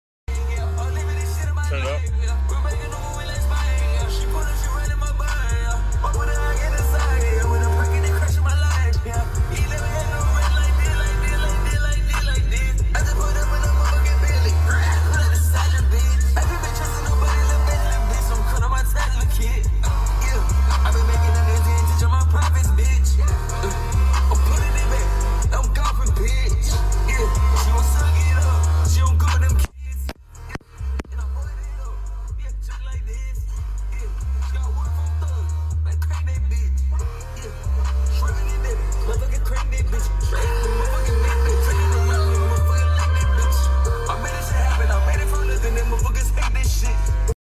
in an Instagram Live